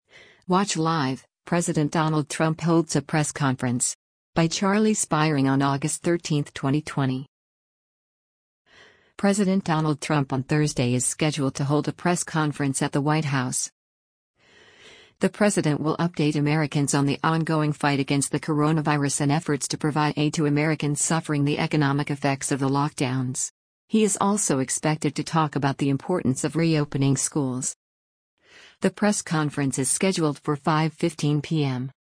President Donald Trump on Thursday is scheduled to hold a press conference at the White House.